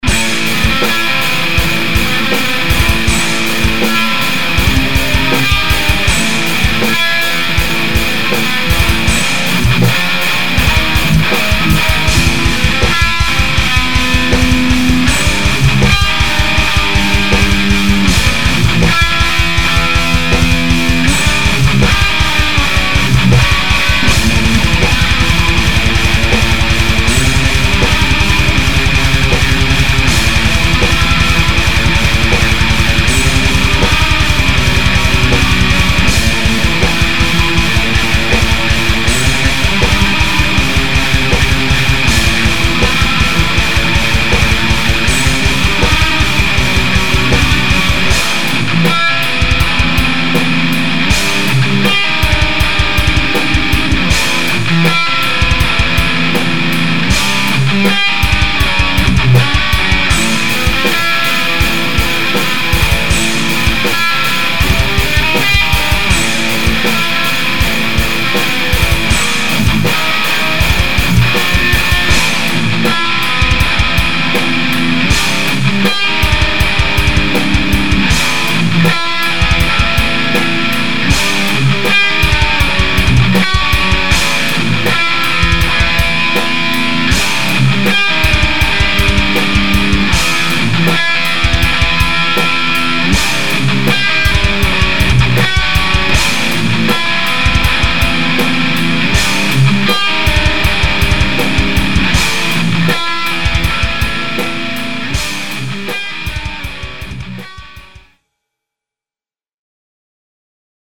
Fixed a few issues with timing and guitar track overlapping.